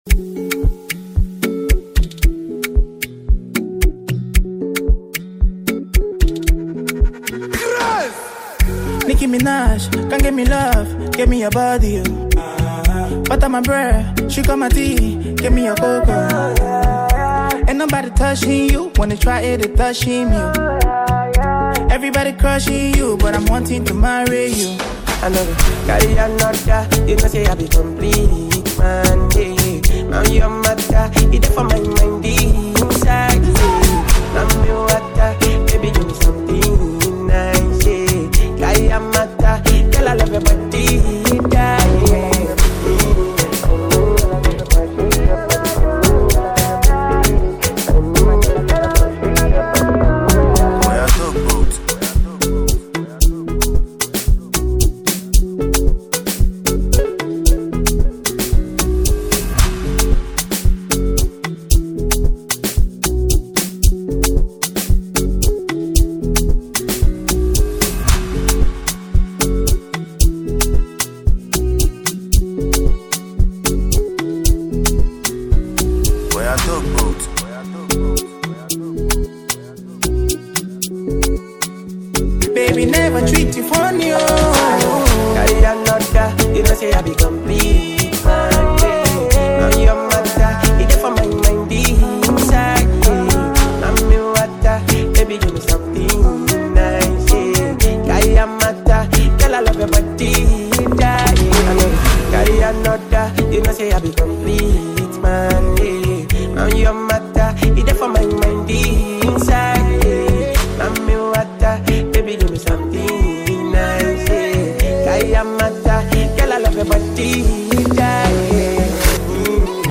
Amapiano Instrumental